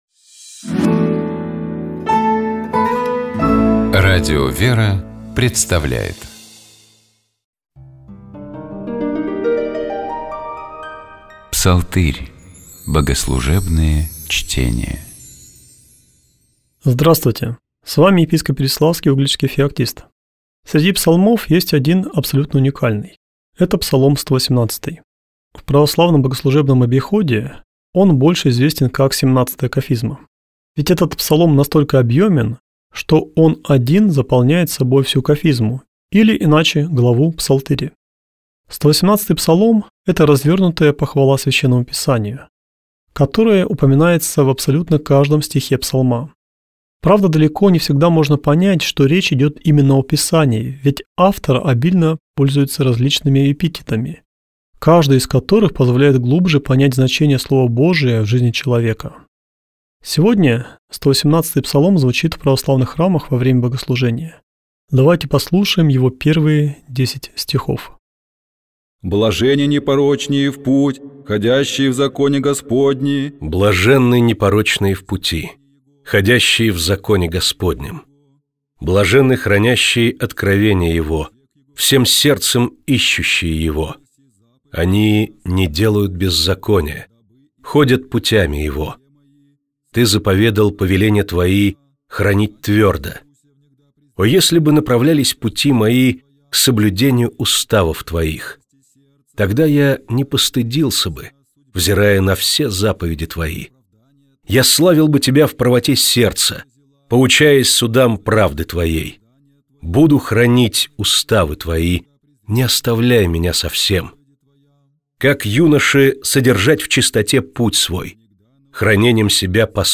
Псалом 118. Богослужебные чтения